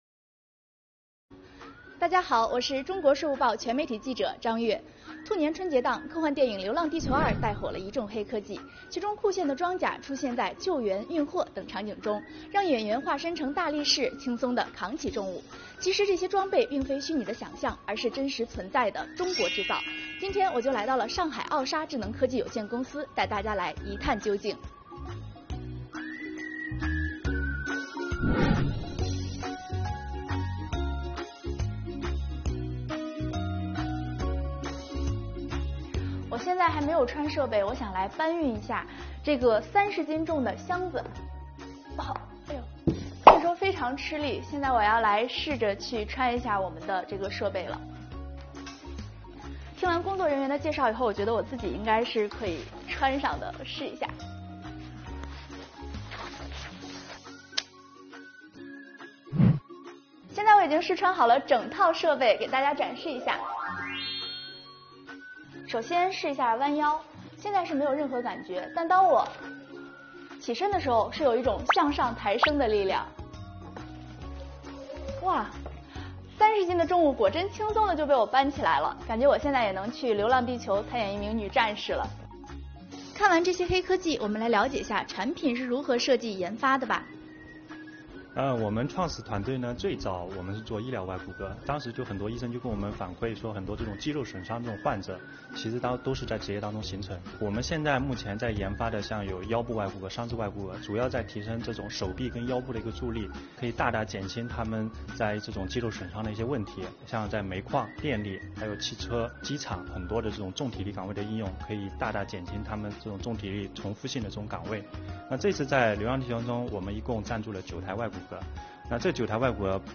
今天记者来到《流浪地球2》中“外骨骼机器人”的设计研发公司——上海傲鲨智能科技有限公司，带大家一探究竟。